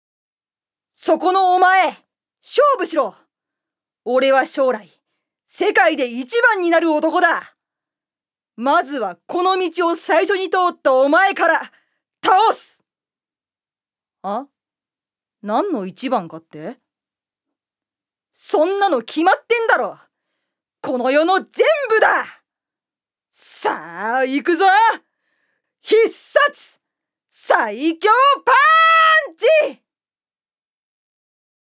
女性タレント
セリフ４